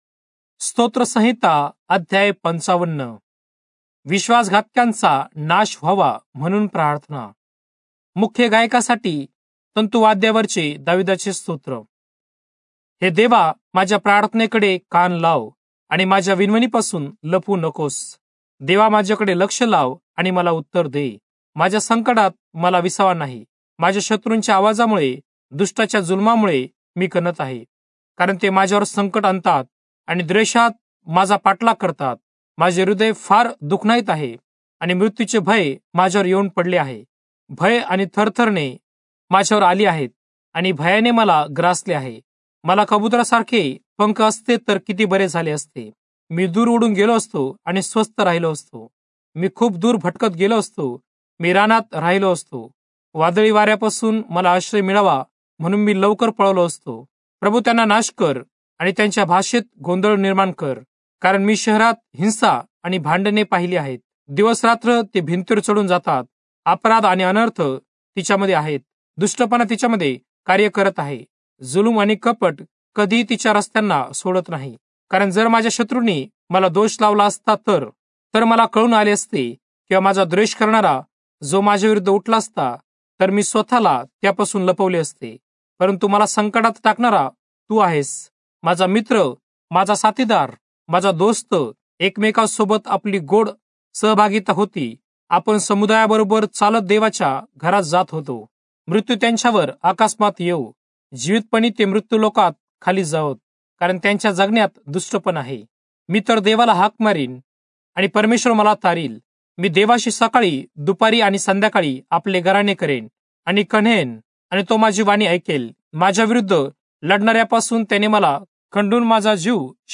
Marathi Audio Bible - Psalms 47 in Irvmr bible version